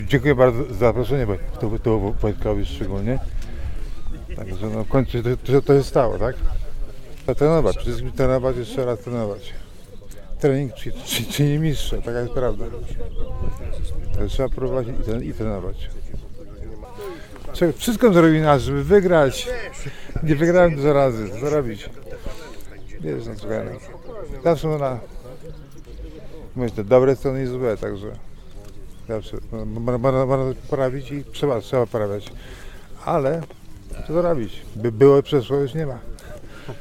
Andrzej Gołota, zapytany o dobrą radę dla aspirujących sportowców, nie tylko pięściarzy, powiedział, że najważniejszy jest trening, „bo trening czyni mistrza”: